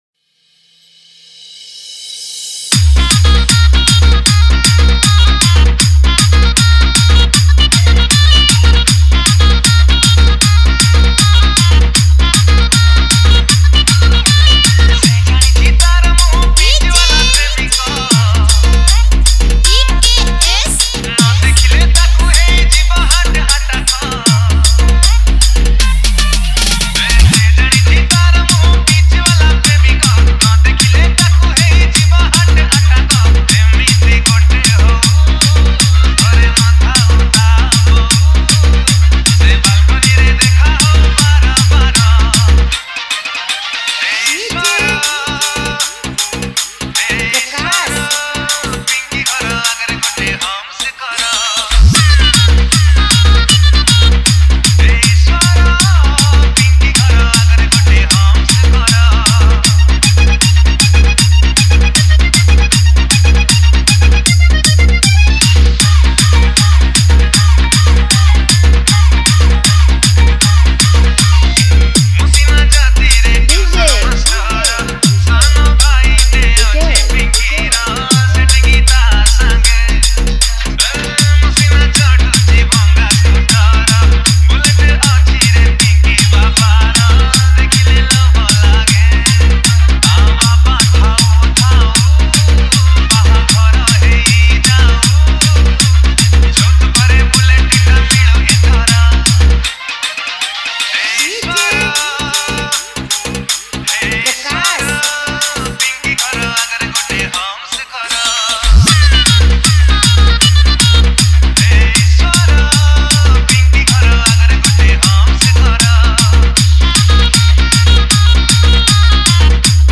Single Dj Song Collection 2022